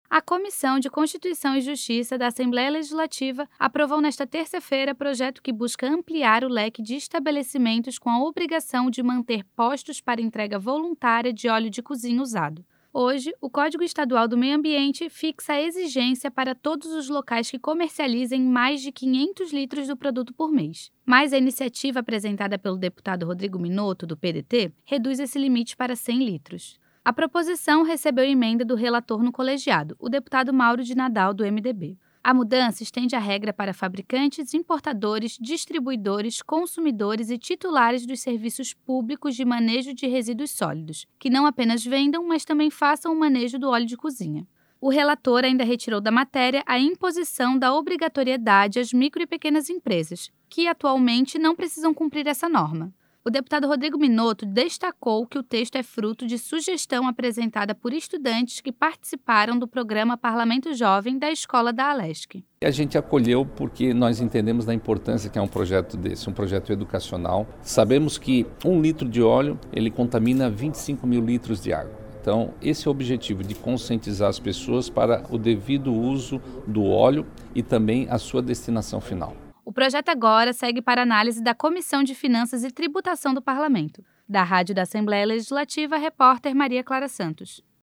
Entrevista com:
- deputado Rodrigo Minotto (PDT), autor do projeto.